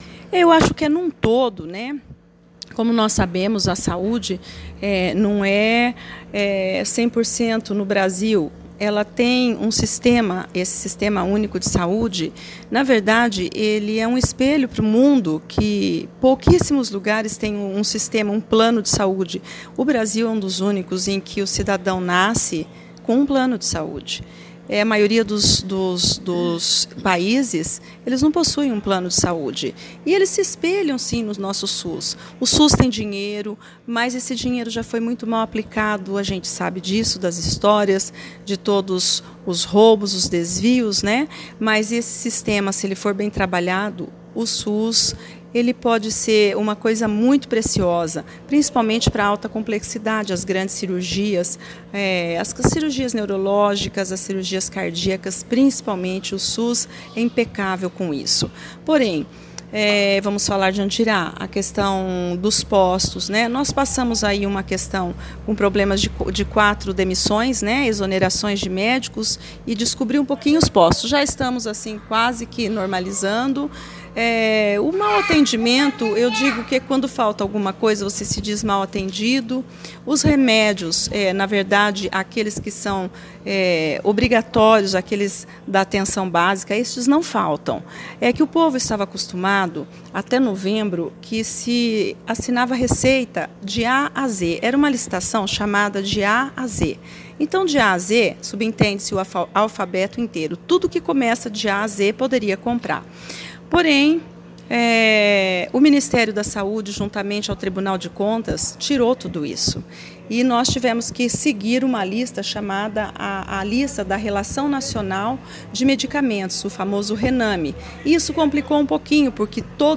A secretária de saúde, Ednyra Godoi – como é conhecida – foi à tribuna e explanou aos vereadores a situação da saúde no município de Andirá, que procuram fazer o máximo para atender a população, mas que alguns imprevistos acabam acontecendo;